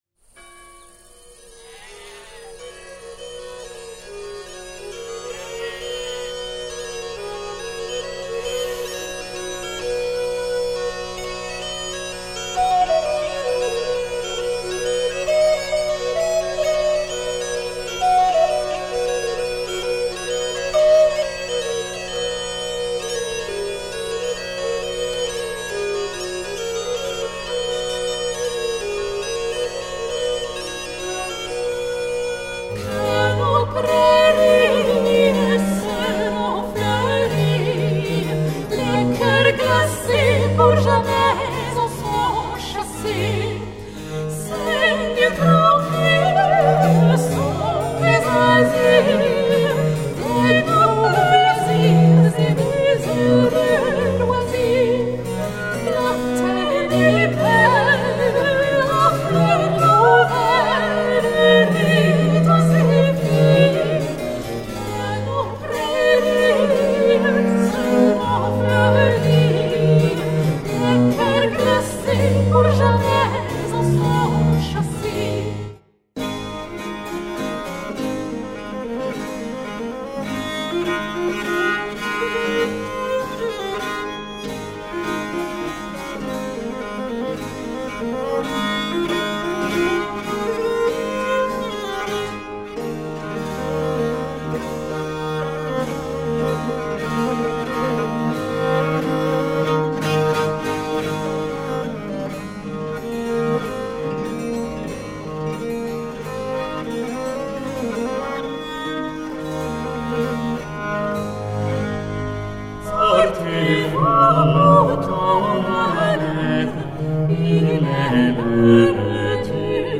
qui a été réalisé à la chapelle de Conflans en octobre 2024.
des duos de bergères
clavecin
viole de gambe